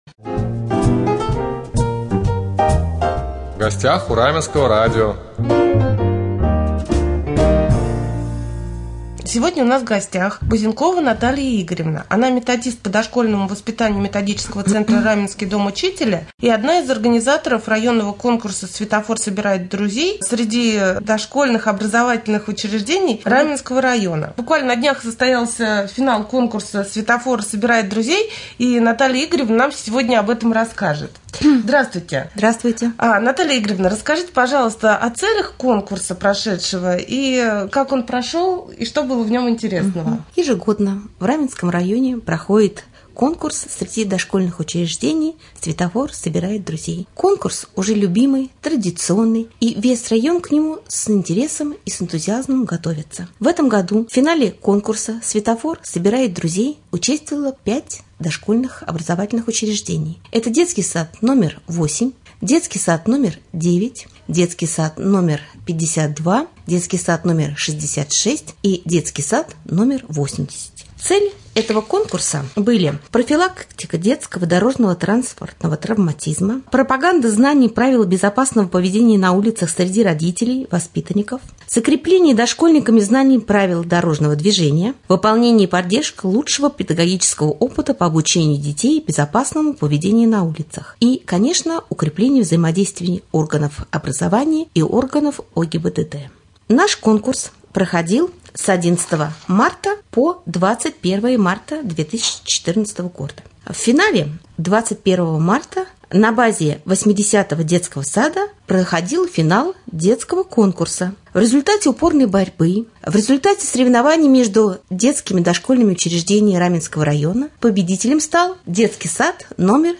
03.04.2014г. в эфире раменского радио - РамМедиа - Раменский муниципальный округ - Раменское